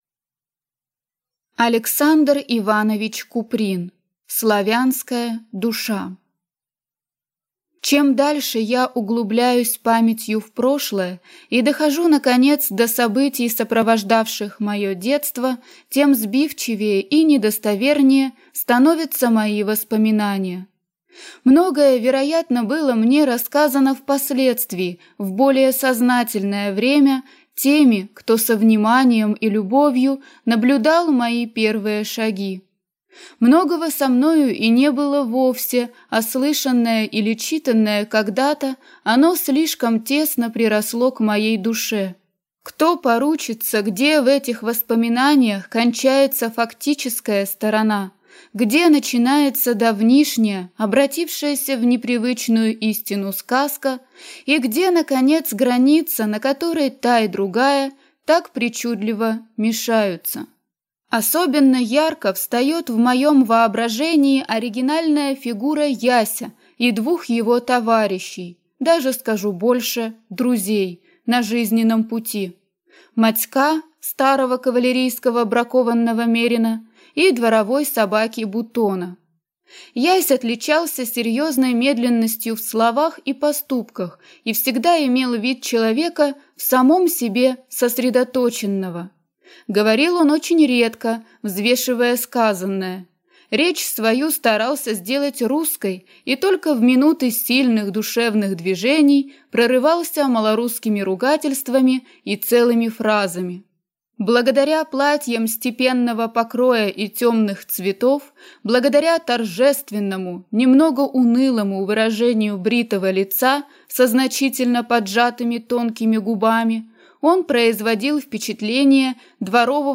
Аудиокнига Славянская душа | Библиотека аудиокниг